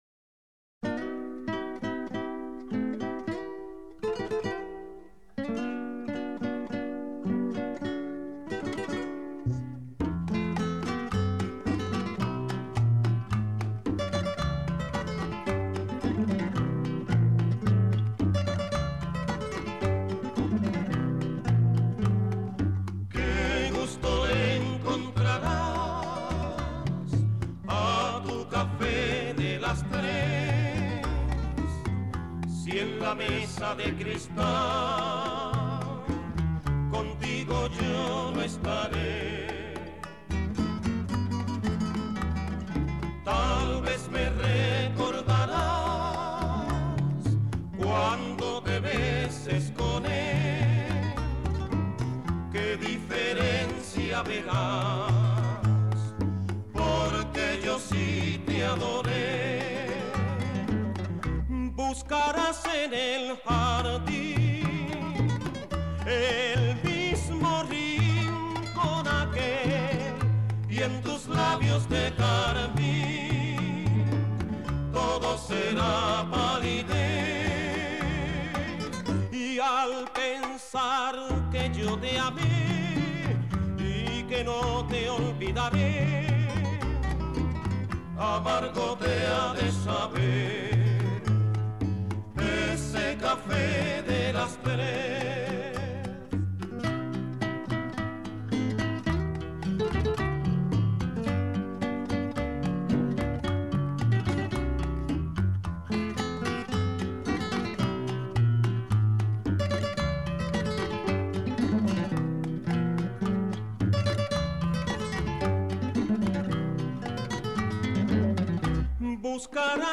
Tríos y Boleros